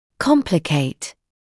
[‘kɔmplɪkeɪt][‘компликейт]осложнять; усложнять, затруднять